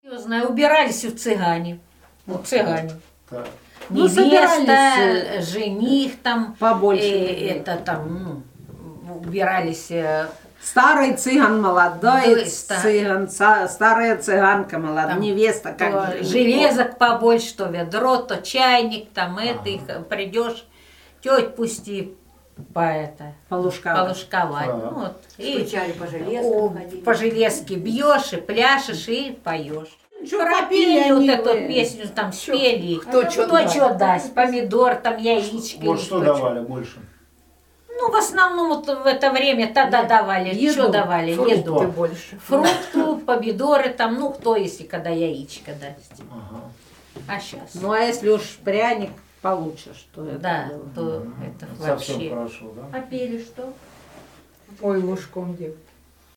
01 Рассказ жительниц хут. Красные ключи Благодарненского р-на Ставропольского края про «лужкование»